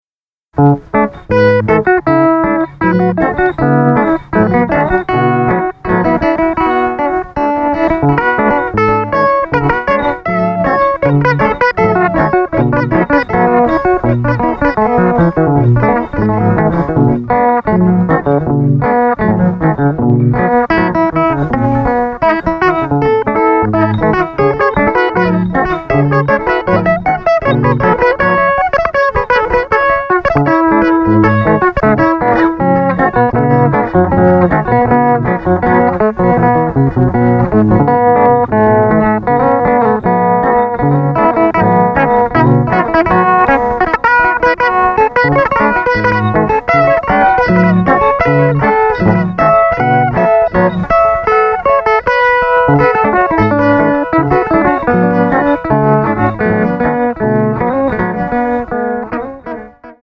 スピード感溢れるミニマル、ノイズ、電子音作品のようなサウンドが◎！